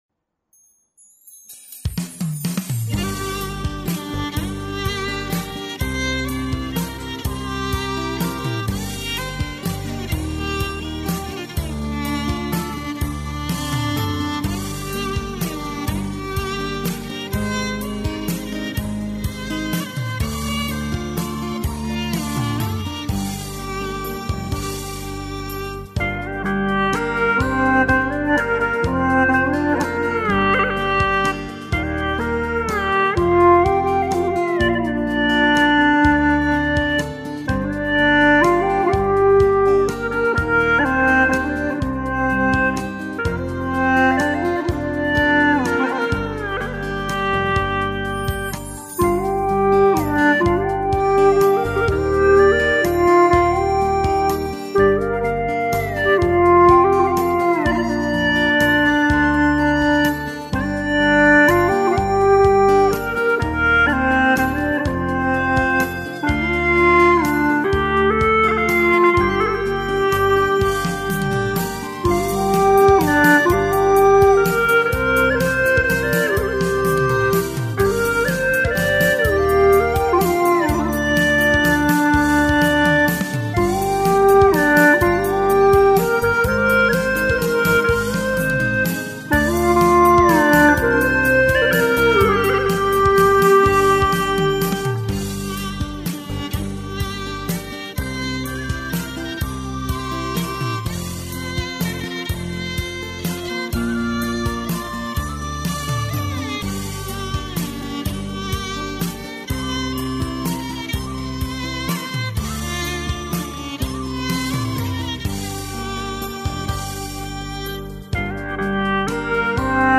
调式 : 降B 曲类 : 流行